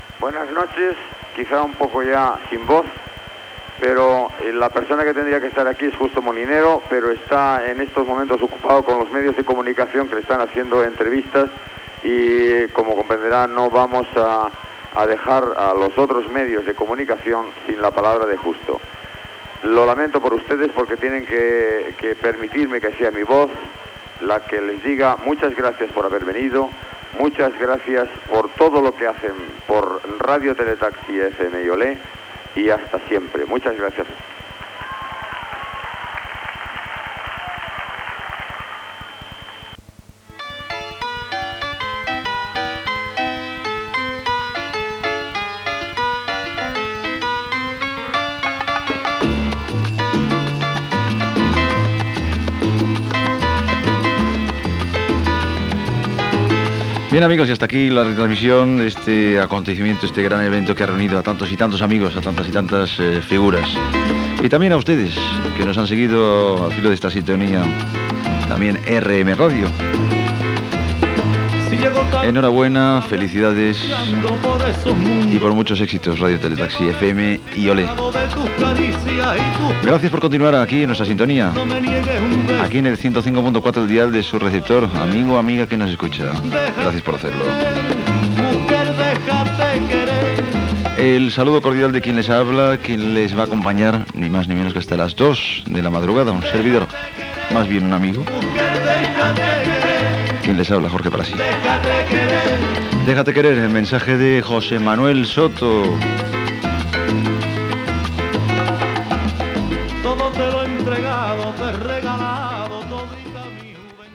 Final de la connexió amb la festa d'inauguració feta a Can Zam de Santa Coloma de Gramenet i represa de la programació des dels estudis. Emissió conjunta RM Radio i Radio Tele Taxi.